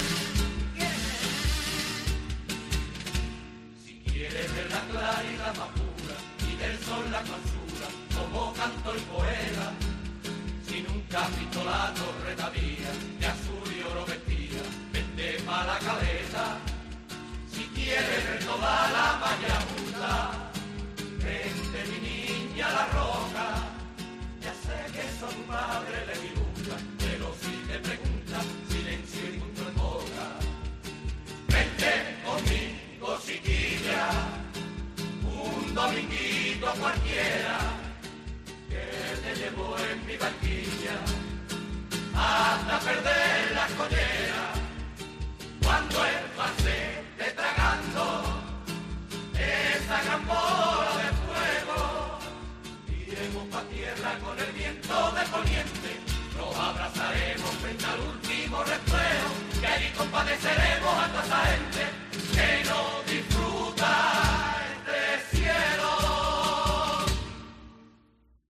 AUDIO: Los Cubatas, chirigota de Paco Rosado